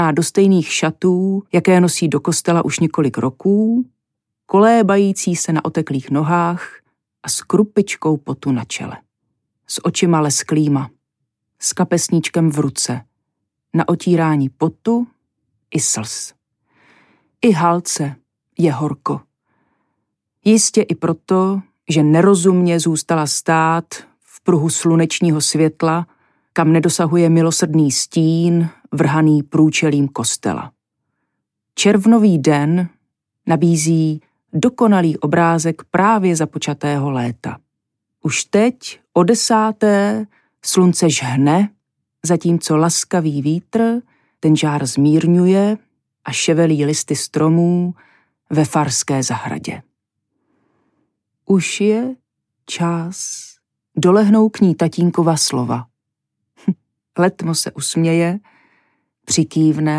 Audiobook
Read: Karin Lednická